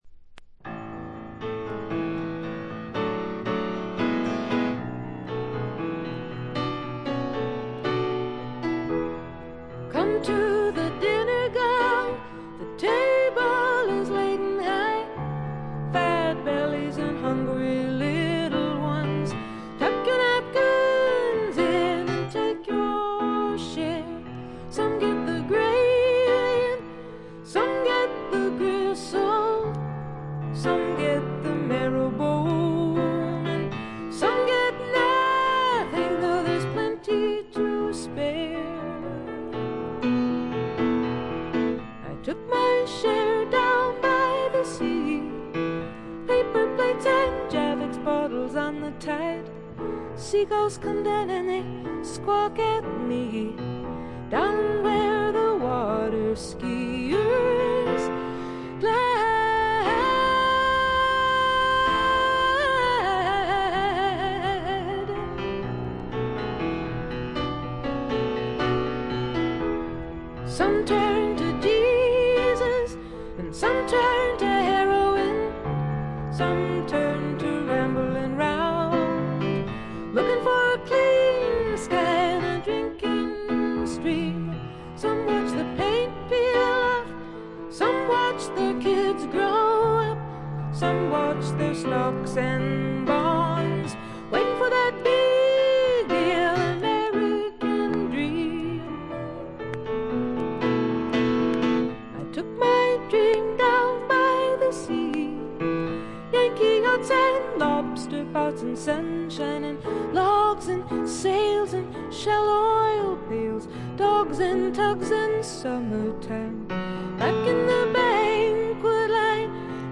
A1終盤の小傷はちょっと目立つプツ音を6回発します。
これ以外も軽いチリプチやプツ音は出ますが鑑賞を妨げるほどではないと思います。
女性シンガーソングライター基本盤。
試聴曲は現品からの取り込み音源です。